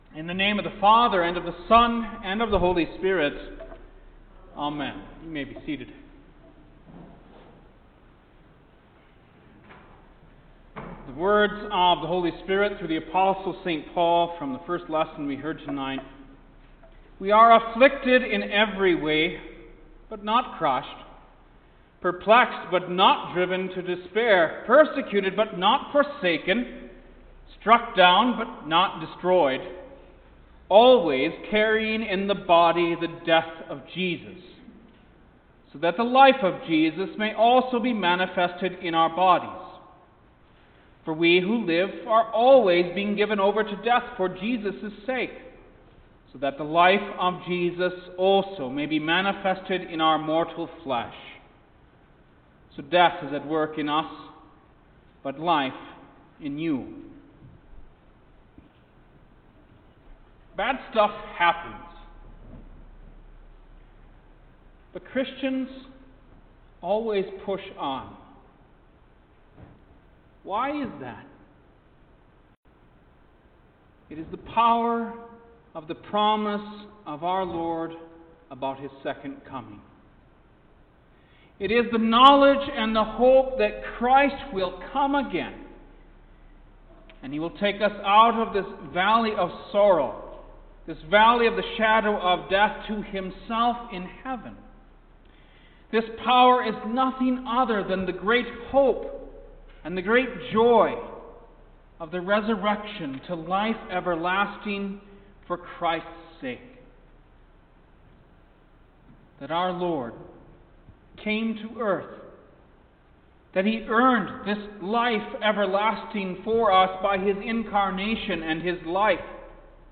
December-16-Third-Midweek-in-Advent-Sermon.mp3